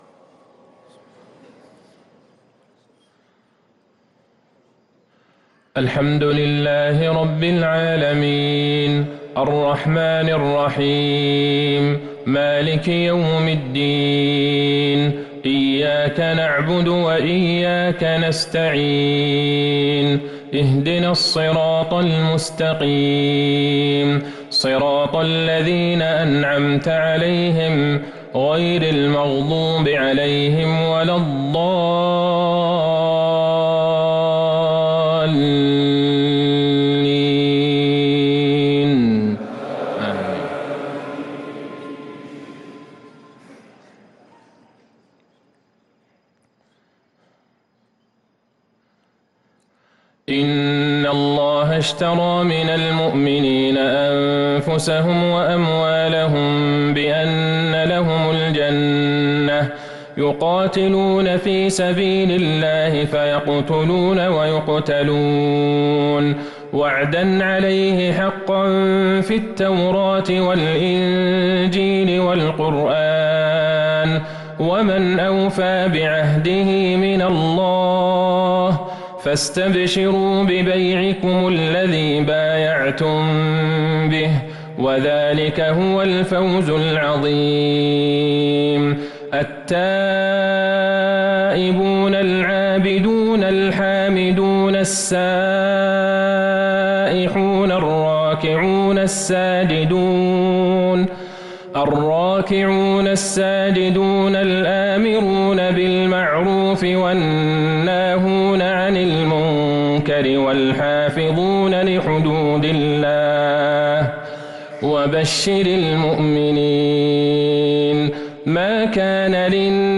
صلاة العشاء للقارئ عبدالله البعيجان 21 شوال 1444 هـ
تِلَاوَات الْحَرَمَيْن .